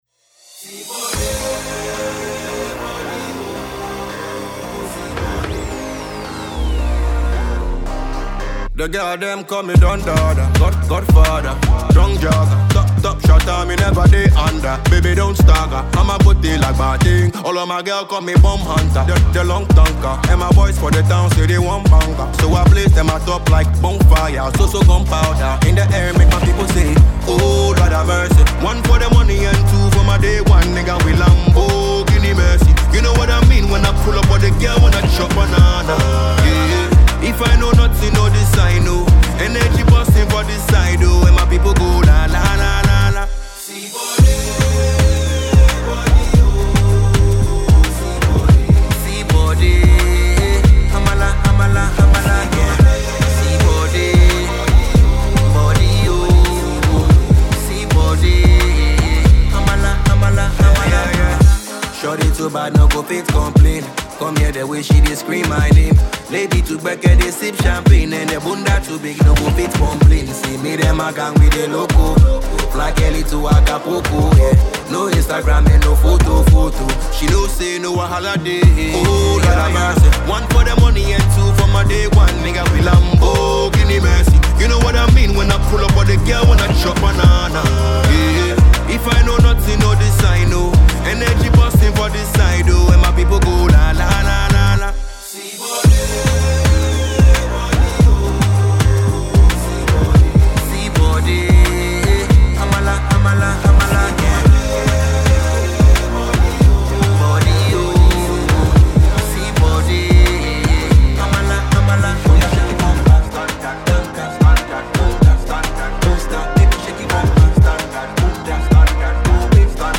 Enjoy this clean production.